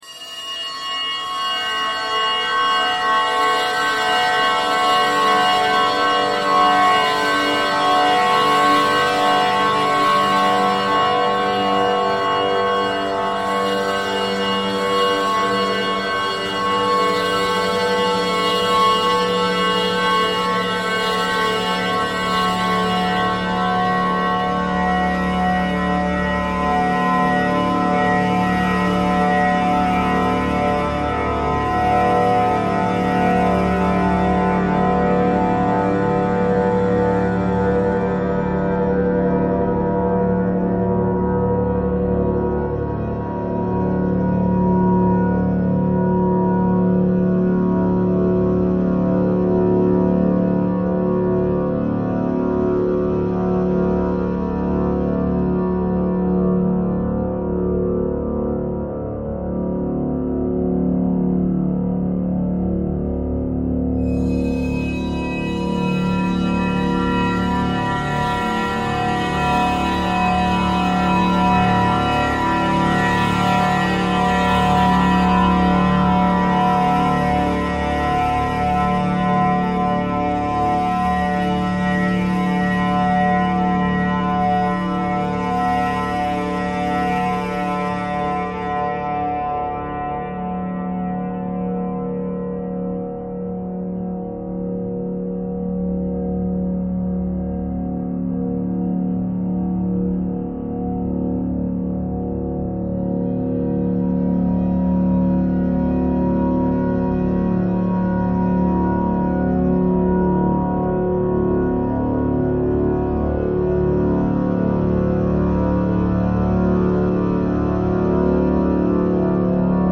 ::: an exercise in spinning thread from recorded sound fragments & then weaving these individual strands into twisted cords ::: an exercise in chance-infused, text-based, generative composition.
cisterncello_cisterncello_thread_simple_all.mp3